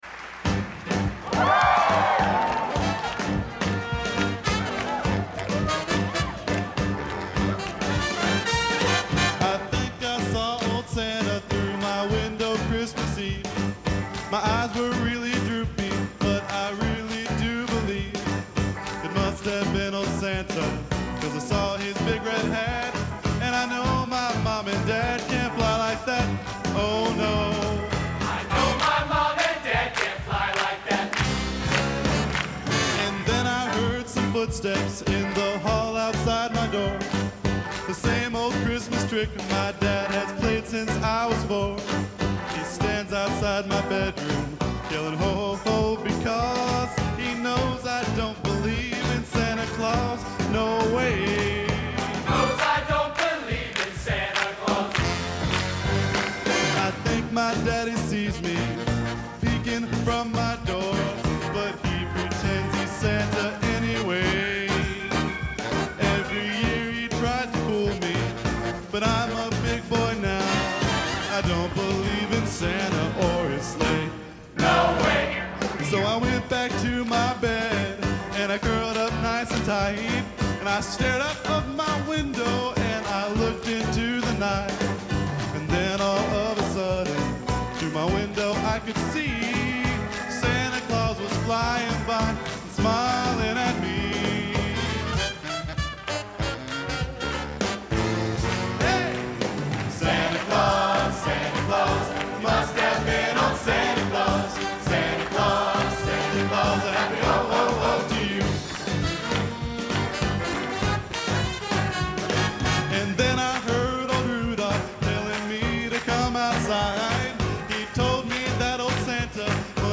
Genre: Holiday | Type: Christmas Show |